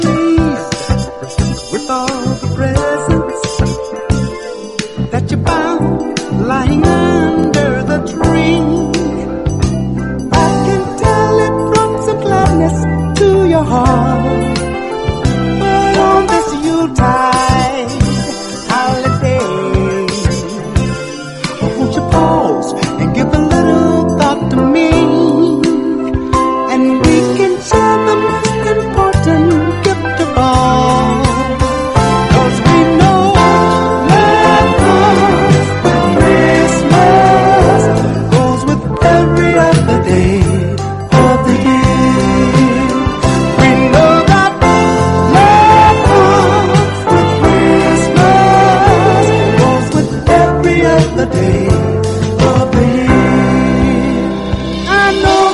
SOUL / RARE GROOVE/FUNK / HIP HOP / REGGAE
全14曲のパーティー・チューンを収録！